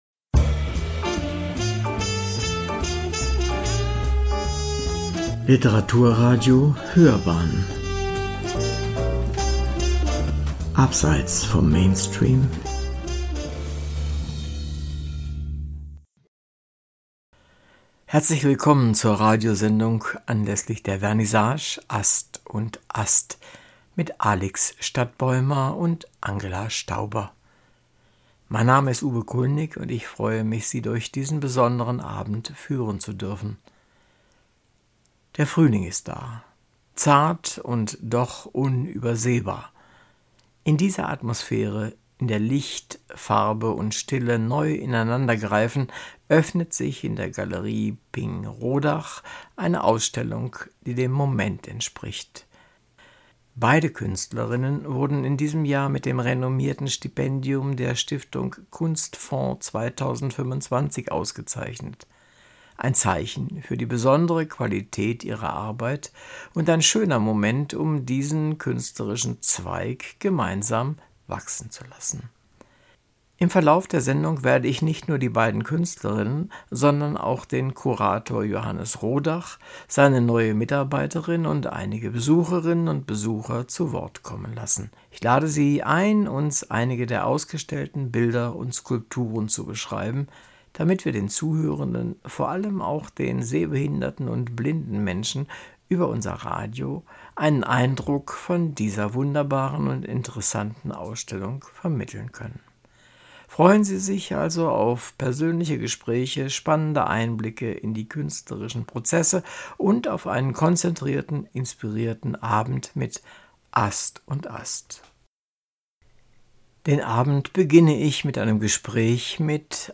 Reportage-Vernissage_AstAst_upload.mp3